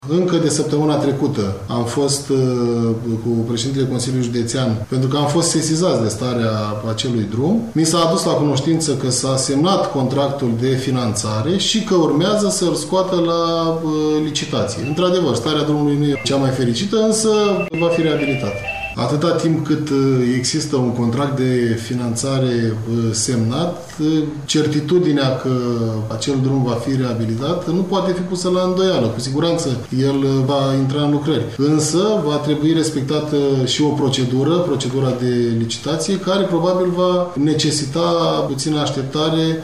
Prefectul judeţului Vaslui, Eduard Popica, a dat asigurări ca în vara acestui an, drumul va fi asfaltat.
8-ian-rdj-17-E-Popica.mp3